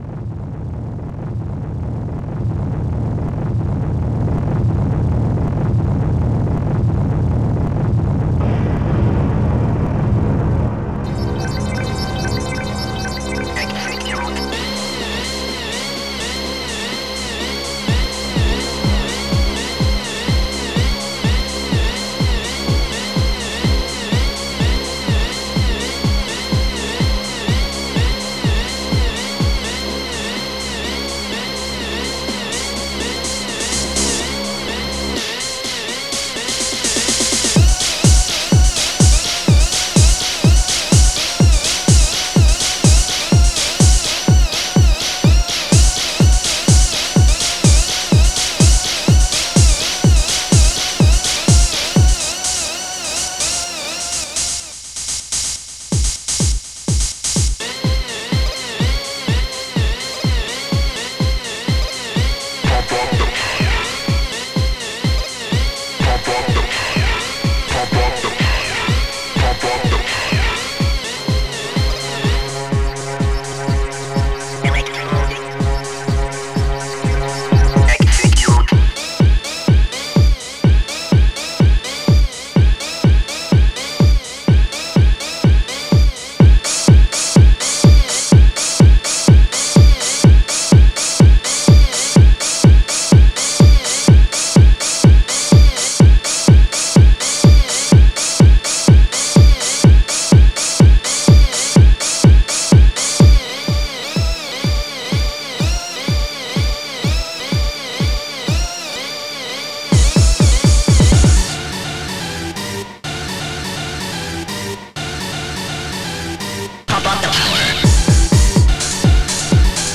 acid_tekno
Digital Symphony Module